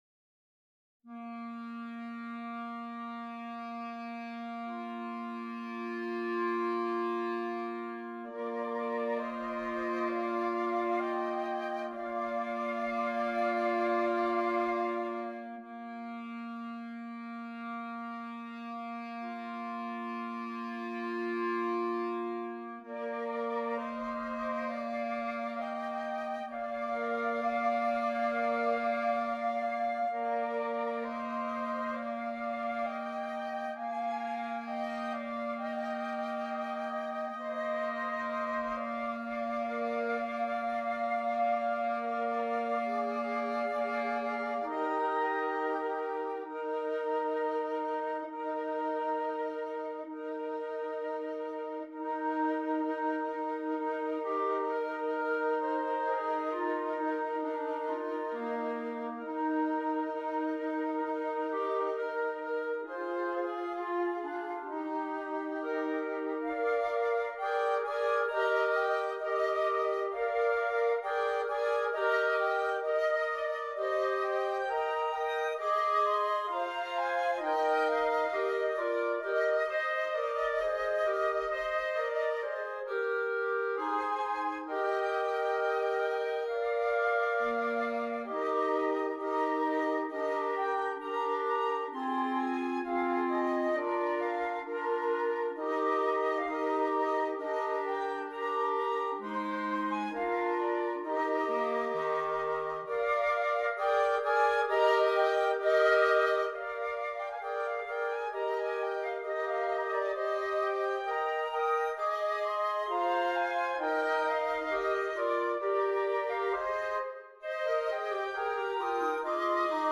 Voicing: Woodwind Quartet